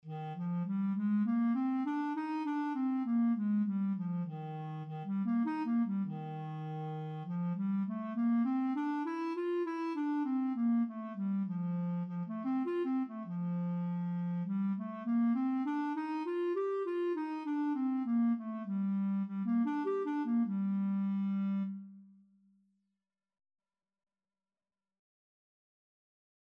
Clarinet scales and arpeggios - Grade 1
Eb4-G5
Eb major (Sounding Pitch) F major (Clarinet in Bb) (View more Eb major Music for Clarinet )
4/4 (View more 4/4 Music)
clarinet_scales_grade1_CL.mp3